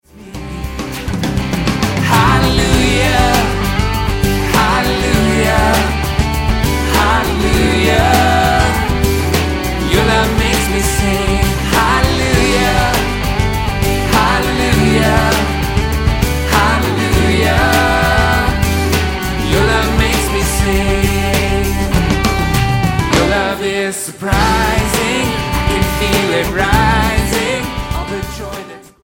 STYLE: Pop
Recorded live at St. Catherine's Church in Dublin, Ireland